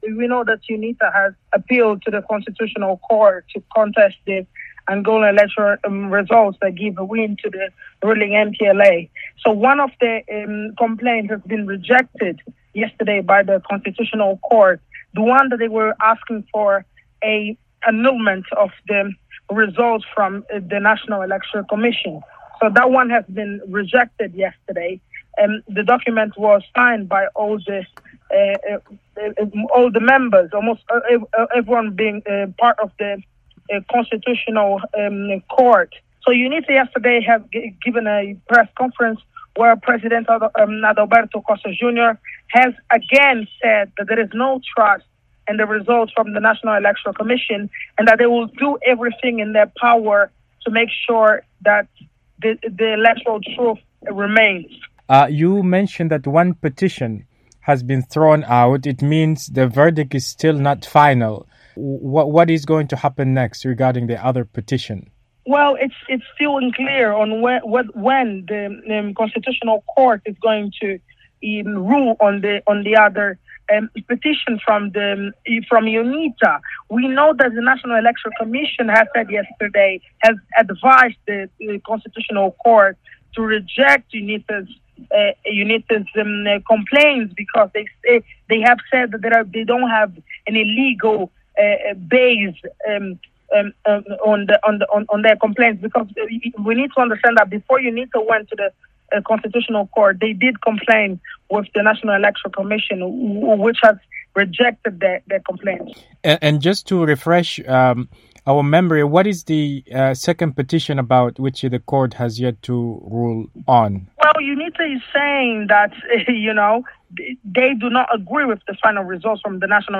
Embed share Q & A – UNITA Mounts Second Constitutional Election Challenge by VOA Africa Embed share The code has been copied to your clipboard.